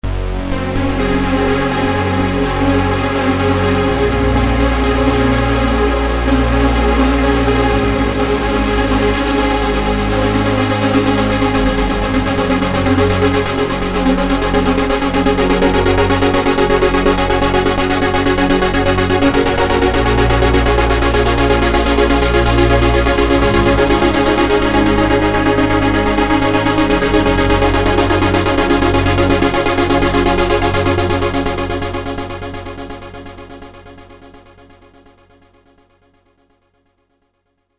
MT-SUPERSAW-SEQUENCE.mp3